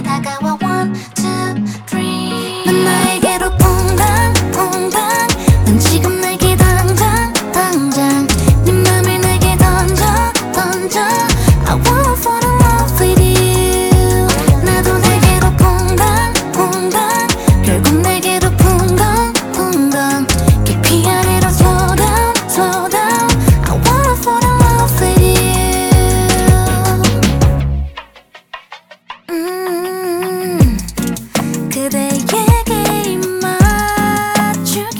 Singer Songwriter Pop K-Pop
Жанр: Поп музыка